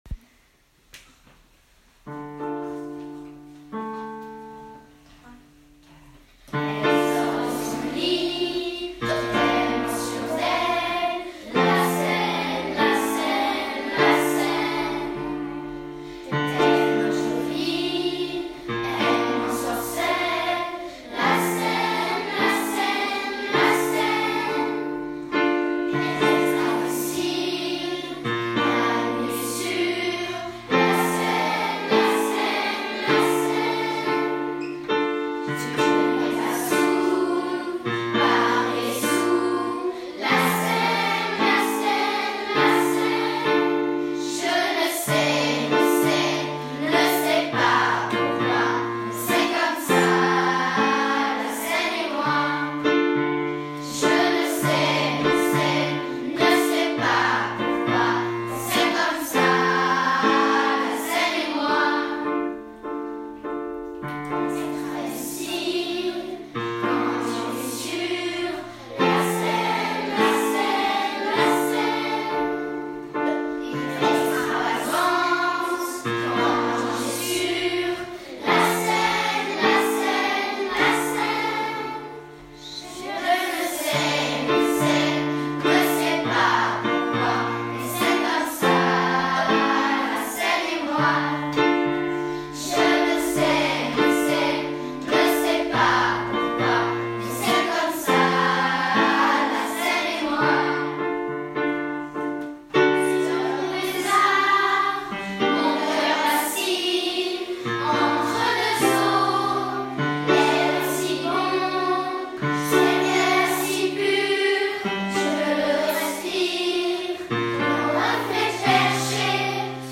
Clique sur le bouton ci-dessous pour écouter ta classe chanter !